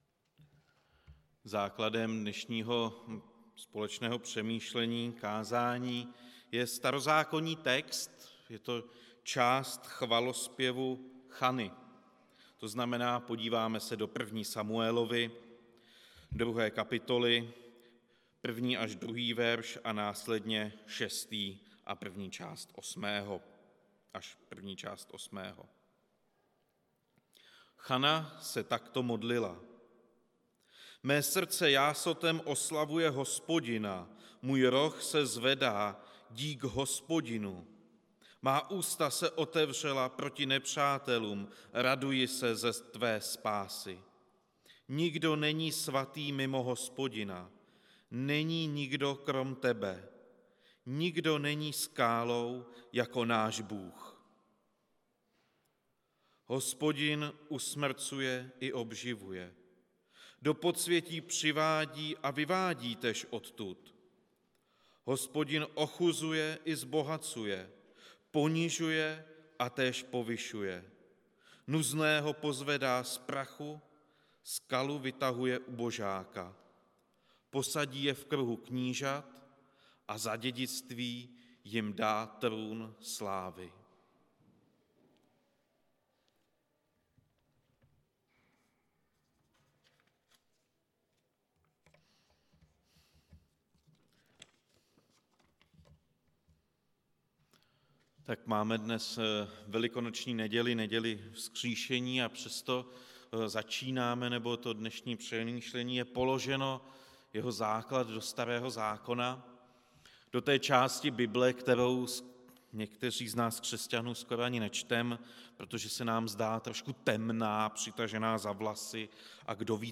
Kázání
Událost: Kázání